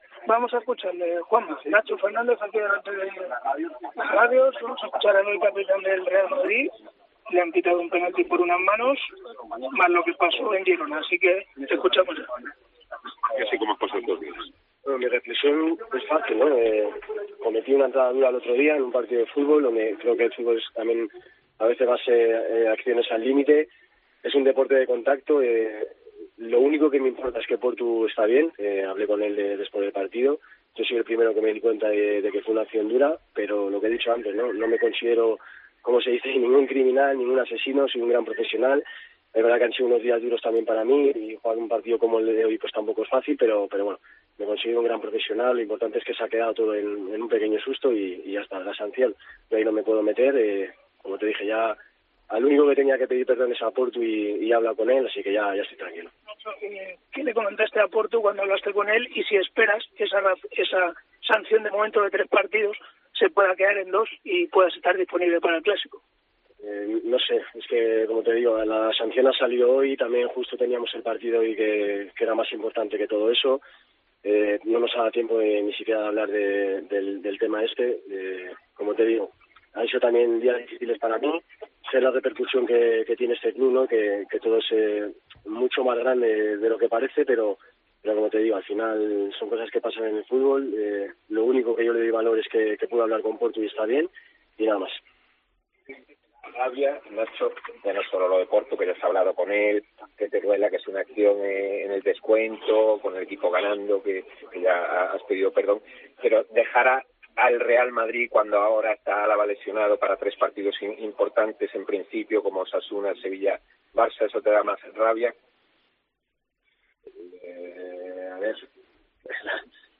AUDIO: El defensa del Real Madrid habló tras la victoria ante el Nápoles sobre la dura entrada a Portu y la sanción de tres partidos del Comité de Competición.